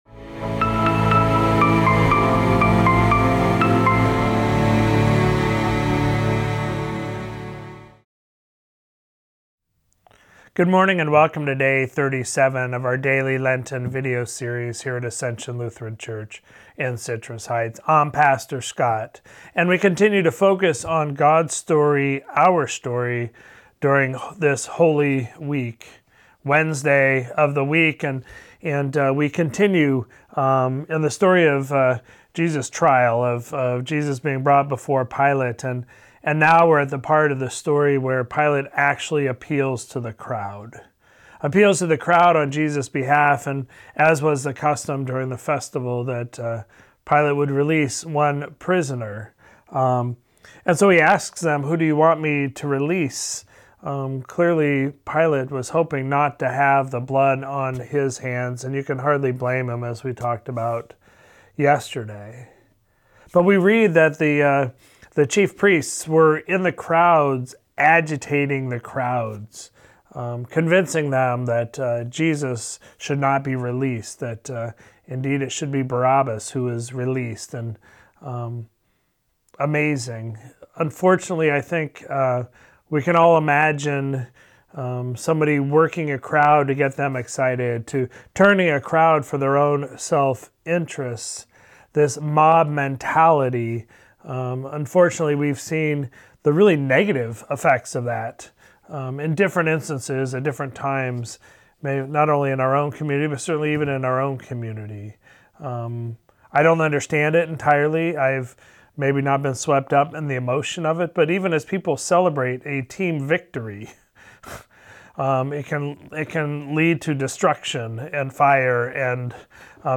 Sermon for Sunday, February 5, 2023